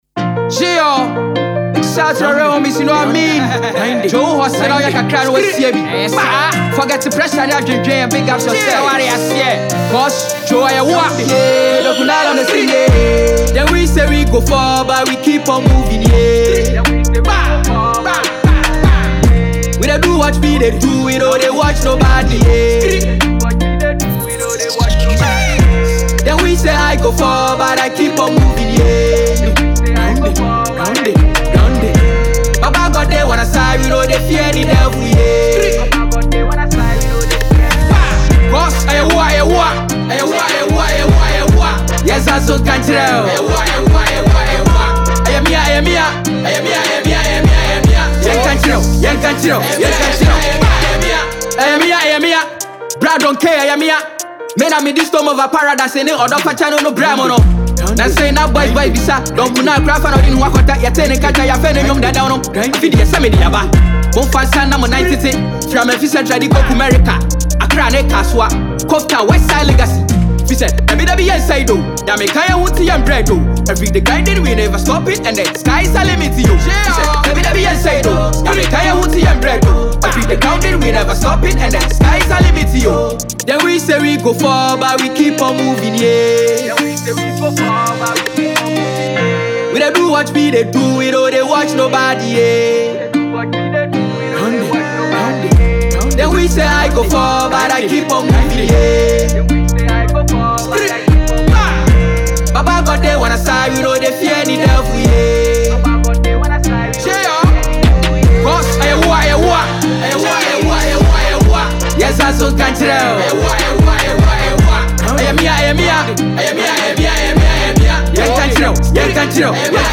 Ghana Music Music
Ghanaian musician based at Tema